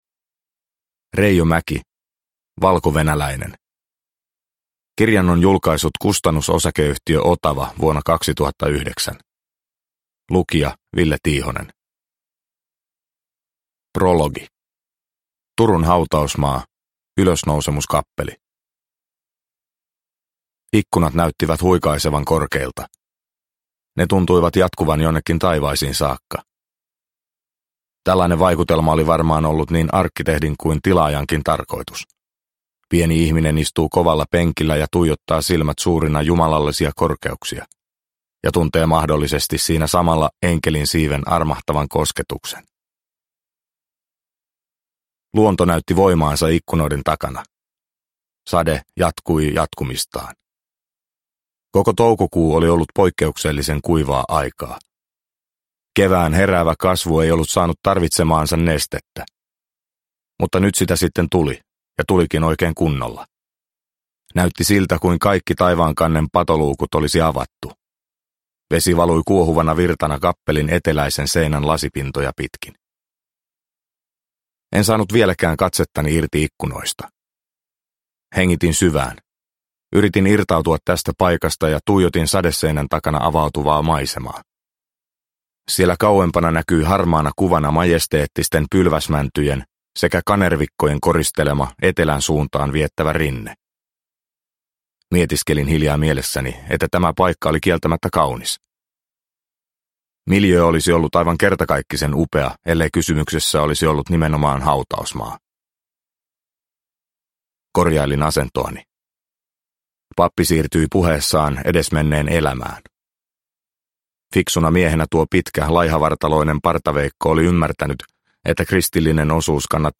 Valkovenäläinen – Ljudbok – Laddas ner